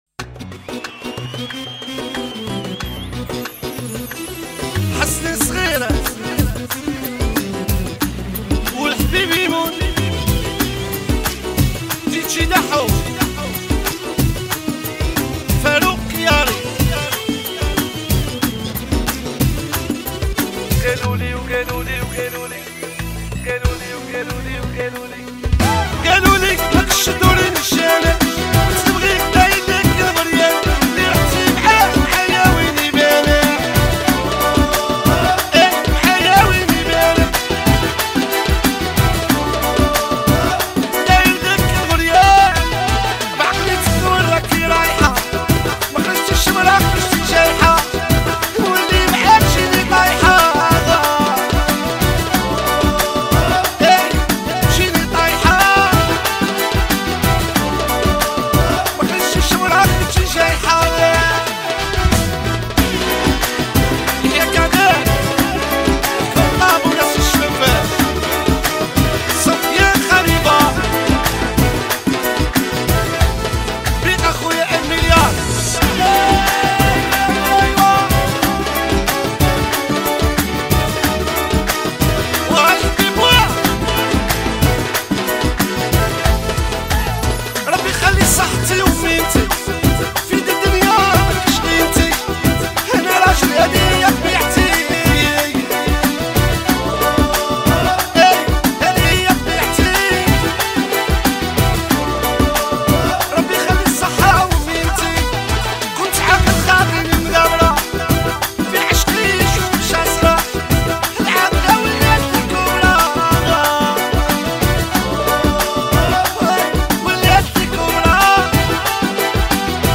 اغاني الراي استماع songالنسخة الأصلية MP3